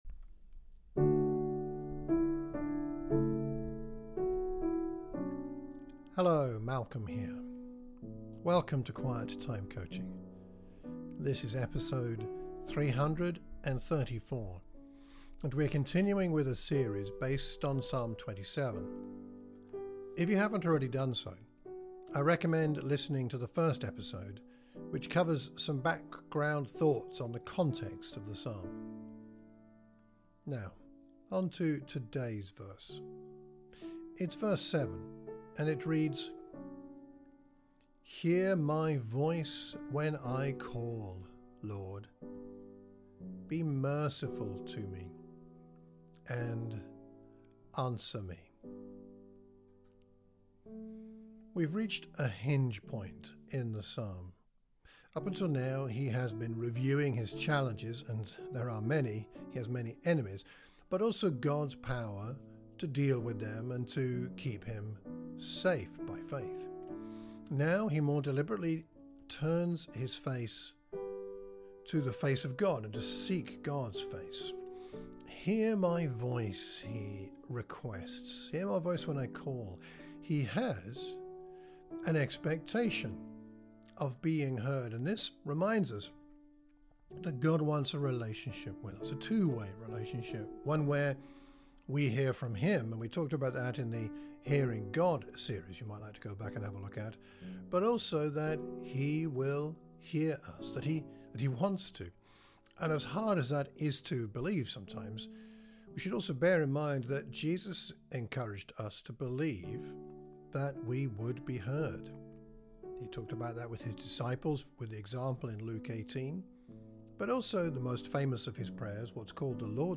We will conclude today’s podcast with the song I wrote using the words of Psalm 27 verse 4.